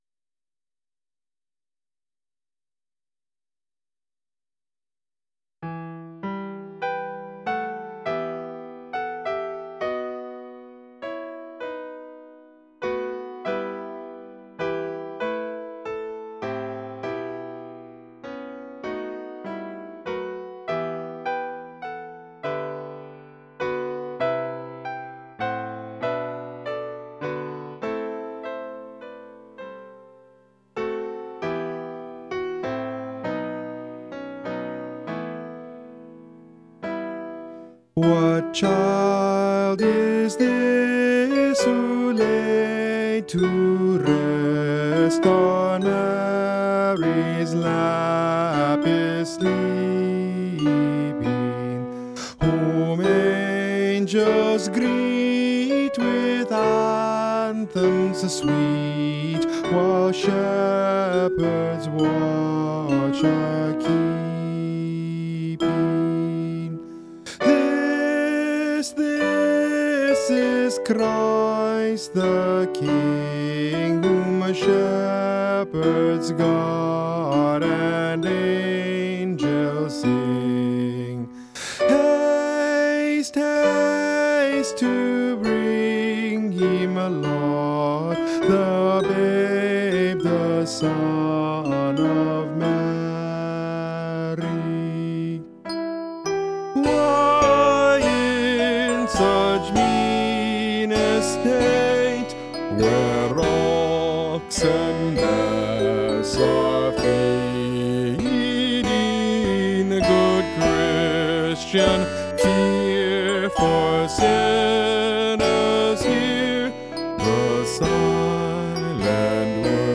For teh Choral versin
S     A      T      B